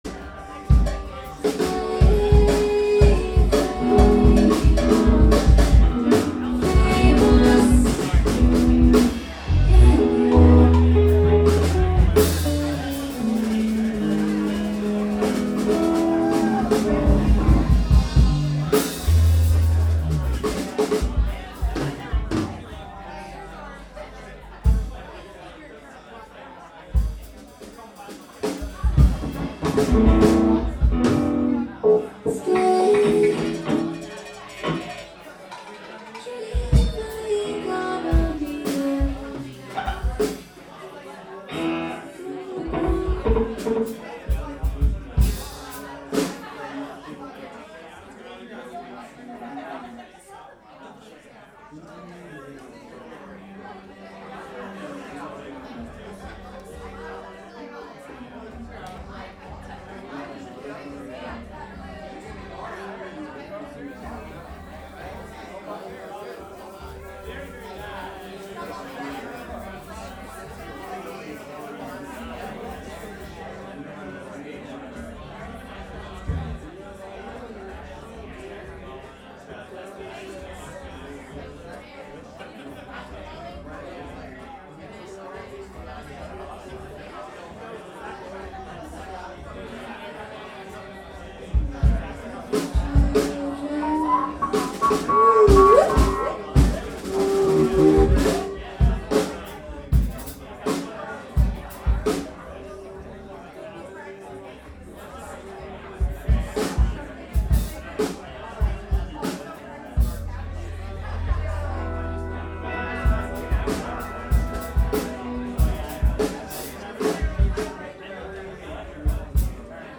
From a live webstream at The Avalon Lounge.